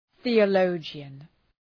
Προφορά
{ɵıə’ləʋdʒıən}